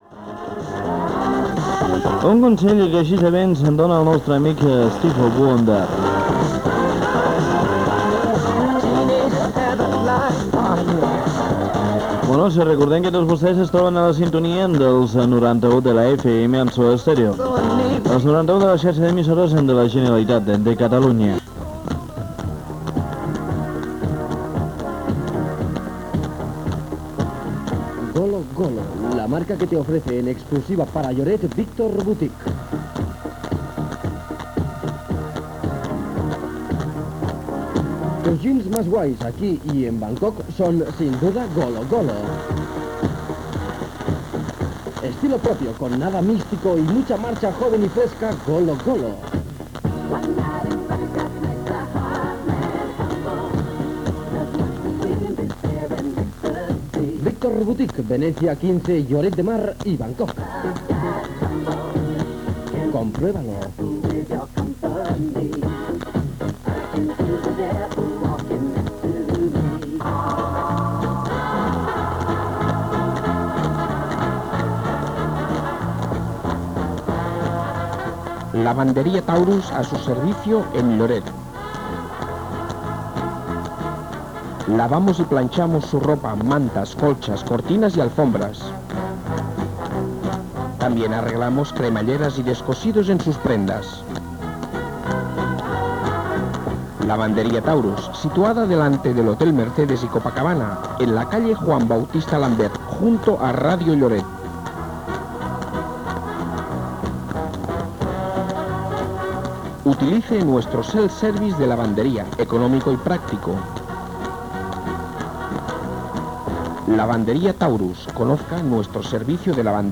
Tema musical, freqüència, publicitat, connexió amb les notícies de Catalunya Ràdio.
FM